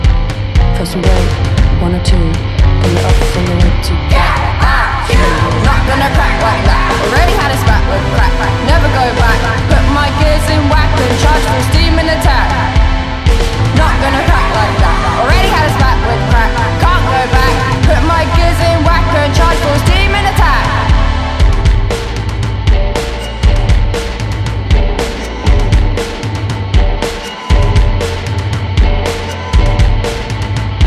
De retour en formation trio